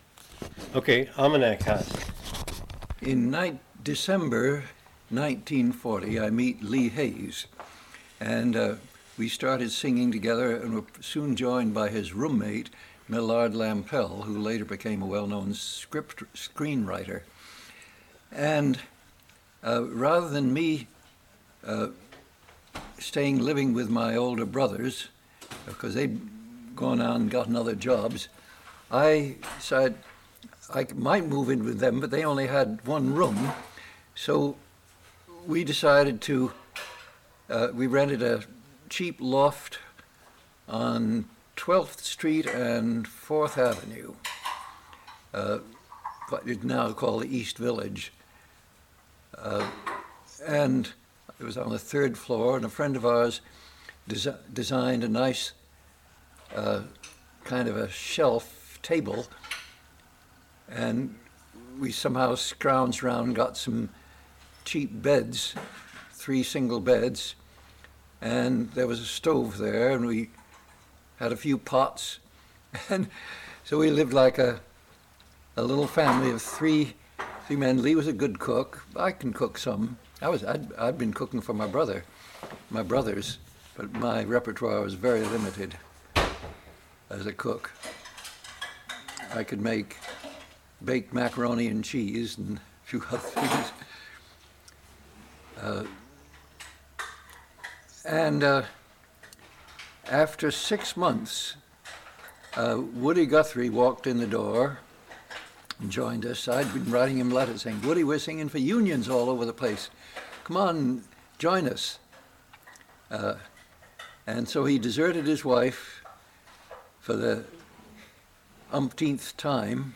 Oral history interview of Pete Seeger